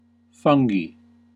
A fungus (pl.: fungi /ˈfʌn/ , /ˈfʌŋɡ/ , /ˈfʌŋɡi/
En-us-fungi-3.ogg.mp3